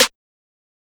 Cardiak Snare (SNARE).wav